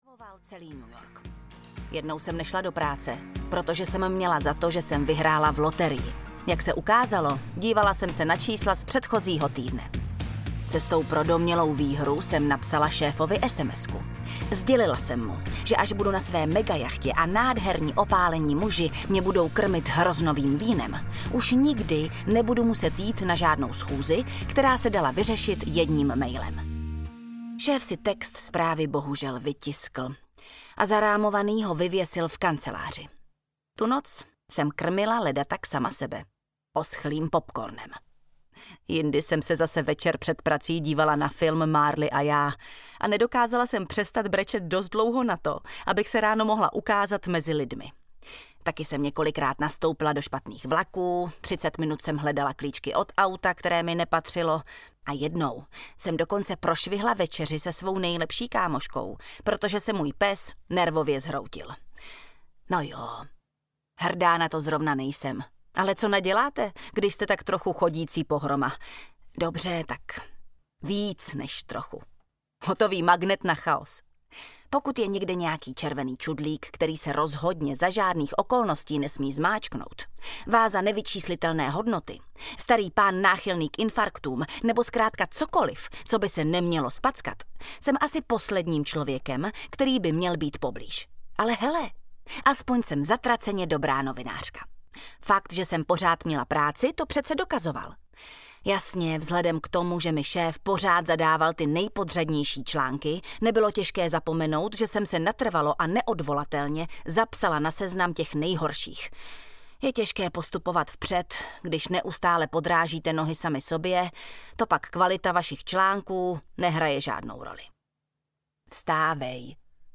Jeho banán audiokniha
Ukázka z knihy